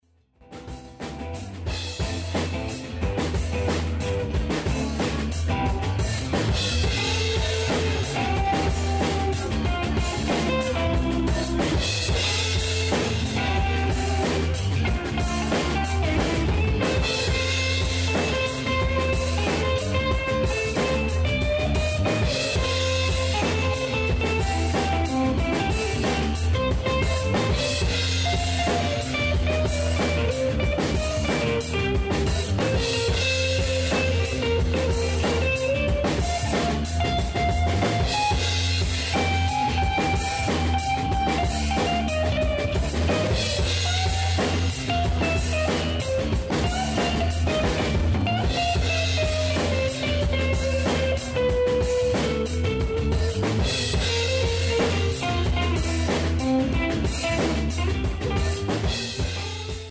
Take It Up A Level Solo Live